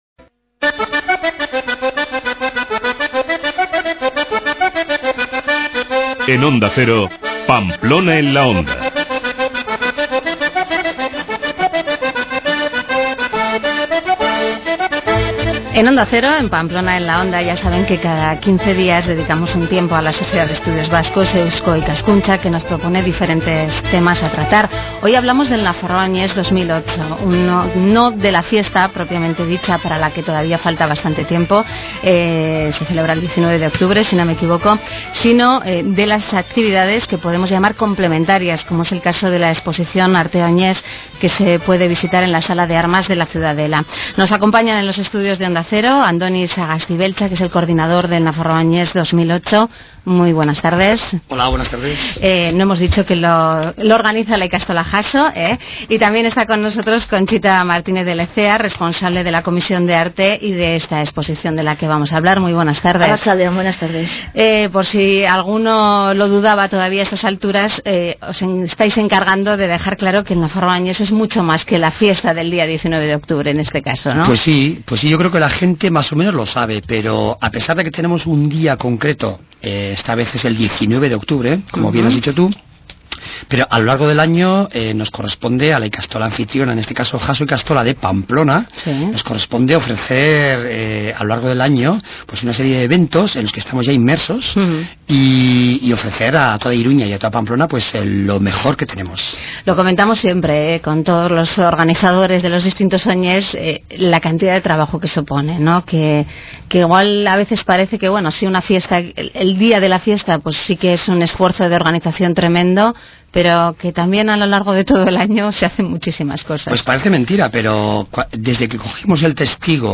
<< Argazki zerrendara bueltatu hurrengoa › Audioa jeitsi << Argazki zerrendara bueltatu hurrengoa › Nafarroa Oinez: arte, música y mucho más Esta tertulia trata de las actividades que se realizan en los meses previos al Nafarroa Oinez, concretamente de la programación de abril: de los conciertos y de la exposición de arte, que cada año va cobrando más importancia.